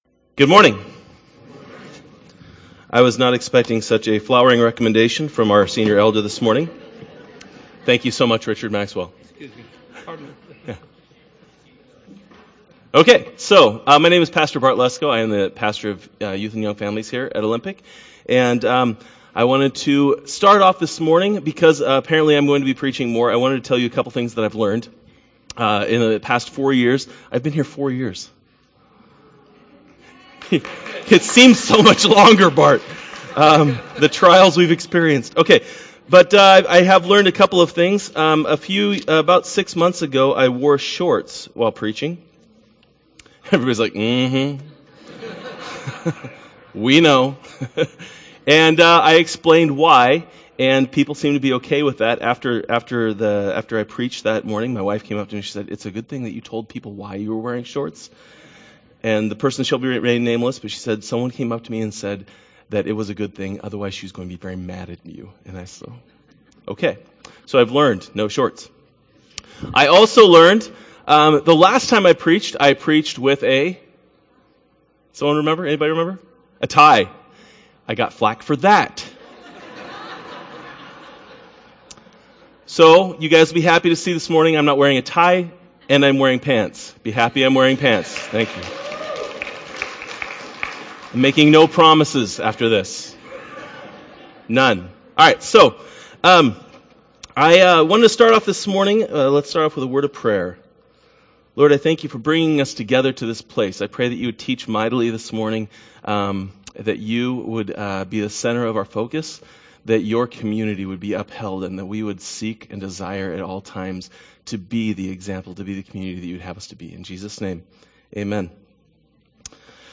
2014 Sermons